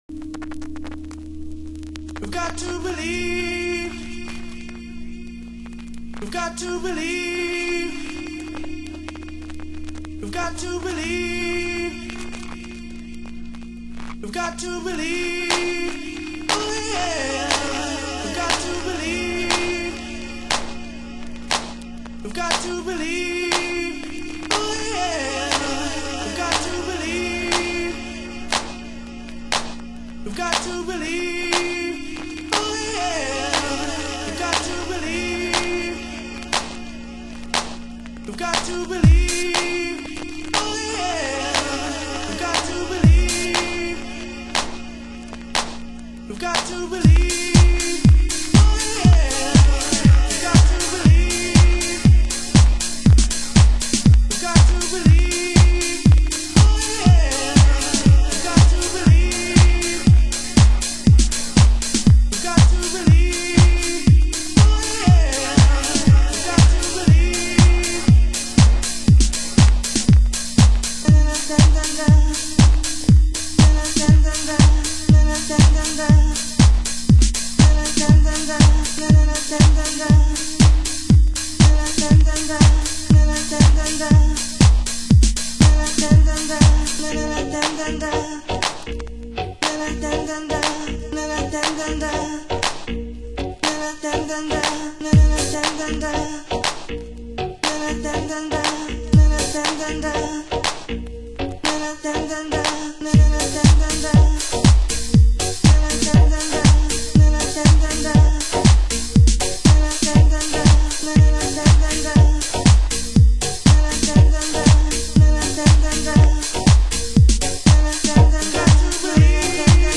盤質：イントロにチリパチノイズ 有/少しチリパチノイズ有